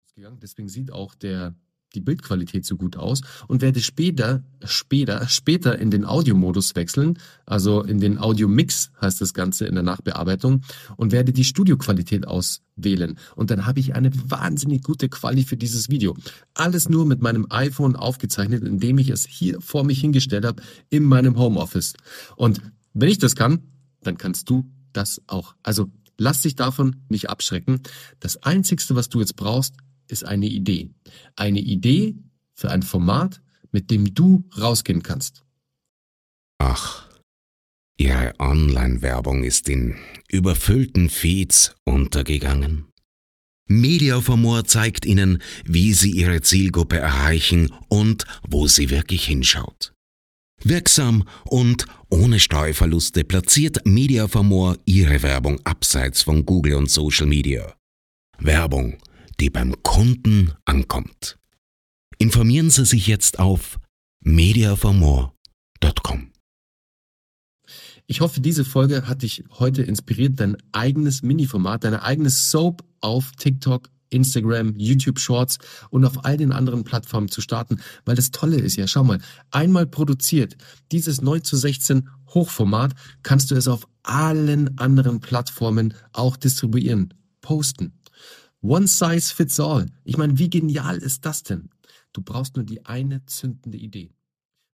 Beispiel Midroll
Podcast_DynamicAds_MIDROLL_Beispielplatzierung_2025.mp3